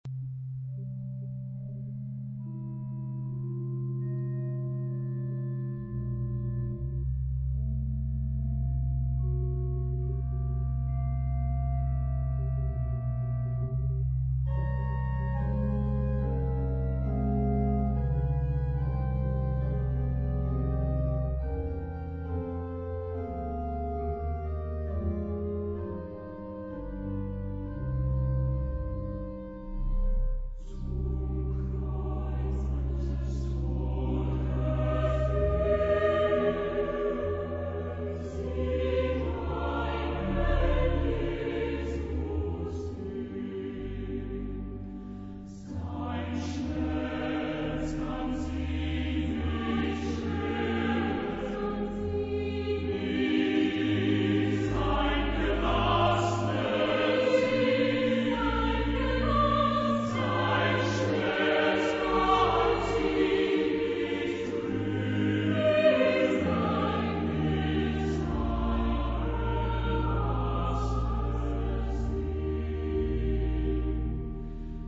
Tipo del material: Coro y organo
Género/Estilo/Forma: Sagrado ; Romántico
Tipo de formación coral: SATB  (4 voces Coro mixto )
Instrumentos: Organo (1)
Tonalidad : do menor